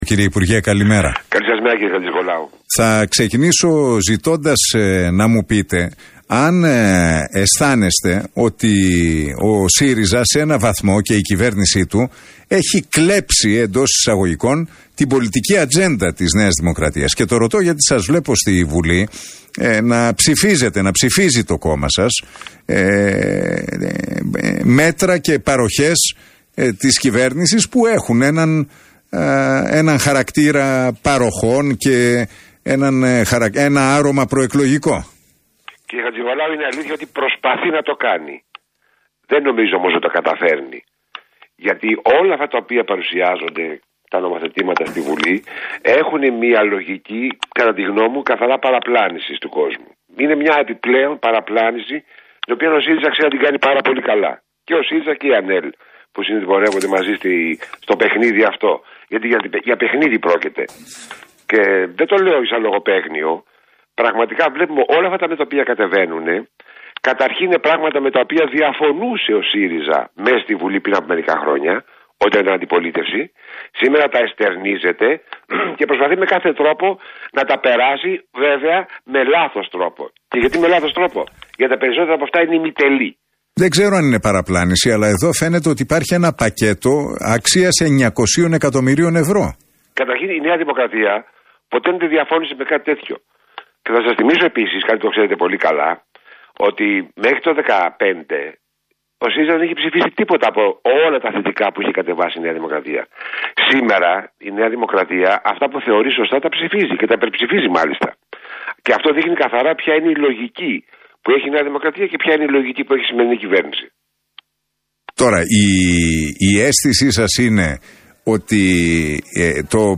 Συνέντευξη για τις τρέχουσες πολιτικές εξελίξεις παραχώρησε στο ραδιόφωνο του RealFM και στον Νίκο Χατζηνικολάου έδωσε ο πρώην υφυπουργός Μεταφορών, Υποδομών και Δικτύων, πρώην βουλευτής Κοζάνης Μιχάλης Παπαδόπουλος. Στο επίκεντρο βρέθηκε η παροχολογία της κυβέρνησης ενόψει εκλογών, η συνταγματική αναθεώρηση , η συμφωνία κράτους – εκκλησίας αλλά και οι μεταγραφές των κομμάτων.